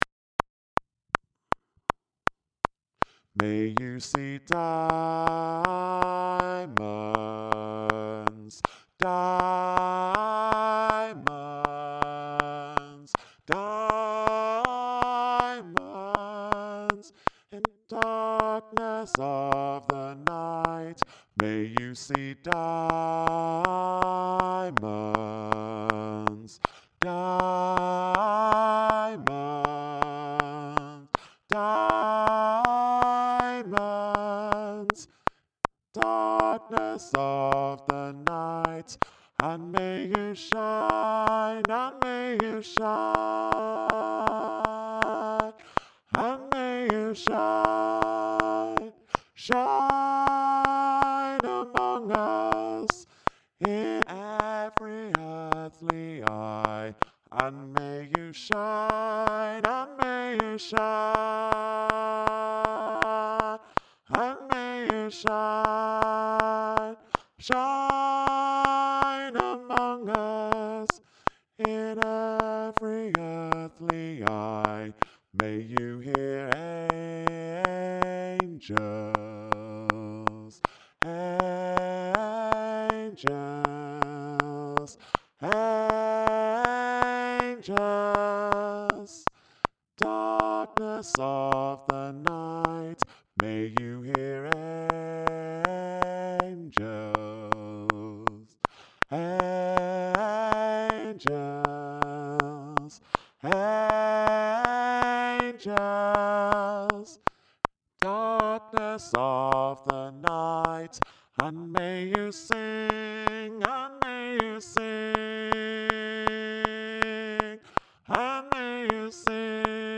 Bass-May-You-See-Diamonds.mp3